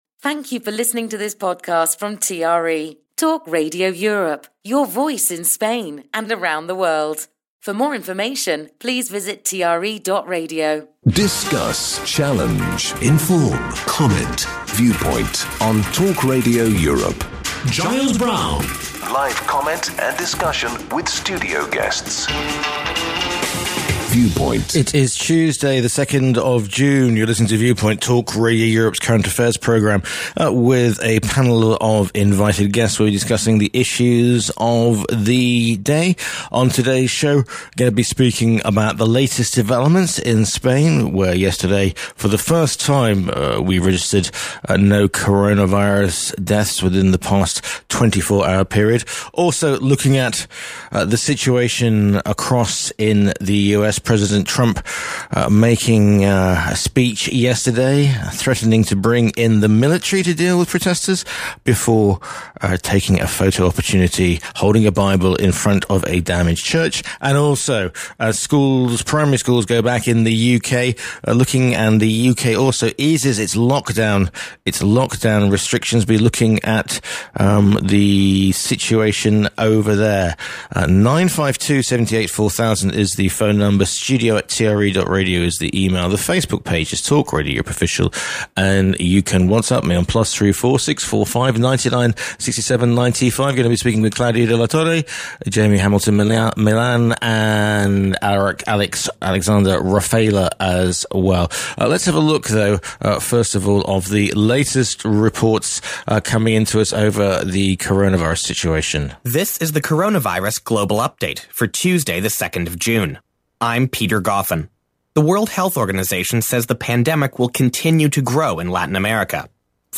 his panel of guests